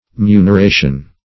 Muneration \Mu`ner*a"tion\